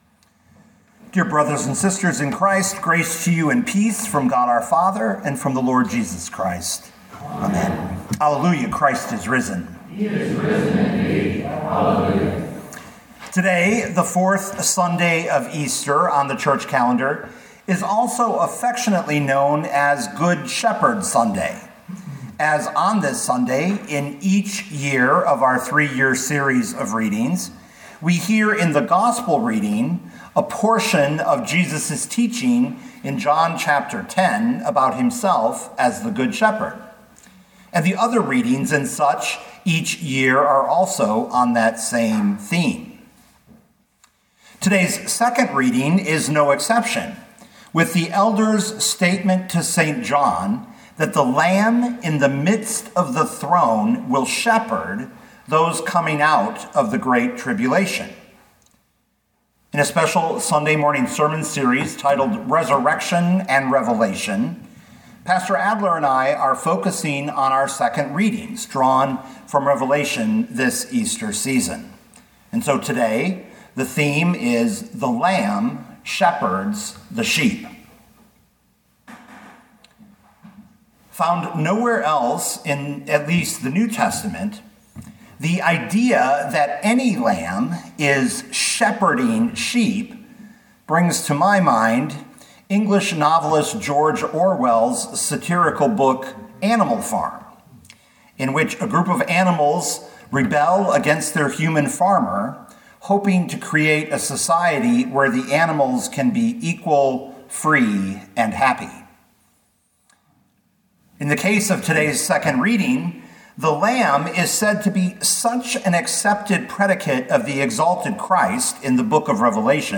2025 Revelation 7:9-17 Listen to the sermon with the player below, or, download the audio.